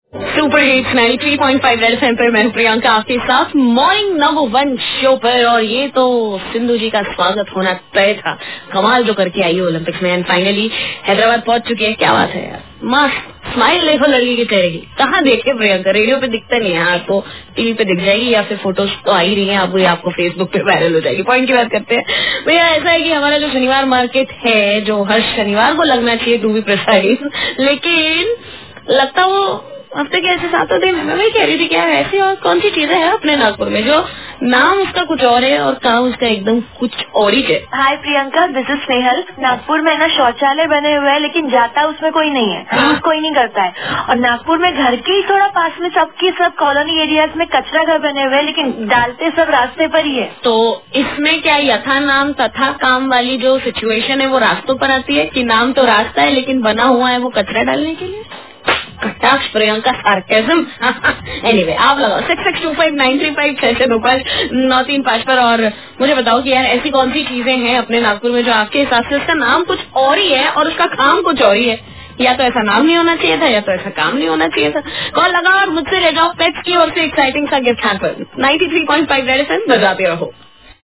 TALKING TO CALLERS